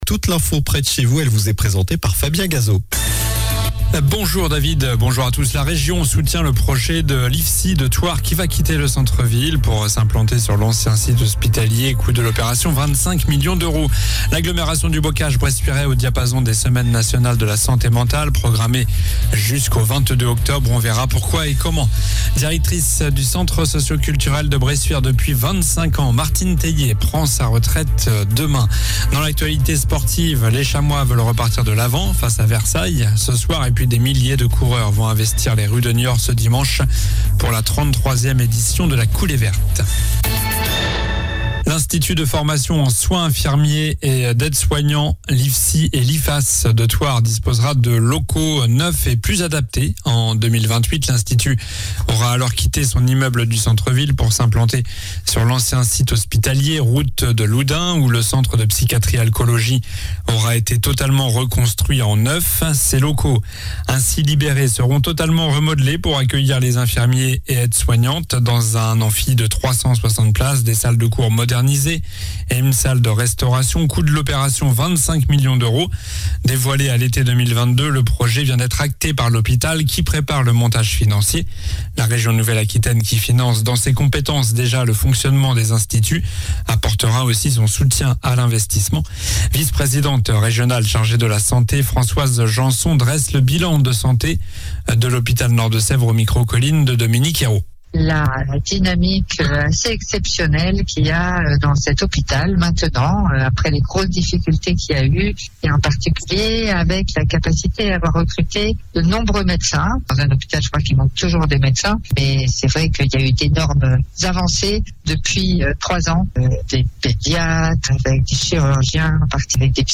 Journal du mercredi 11 octobre (midi)